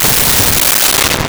Boom
boom.wav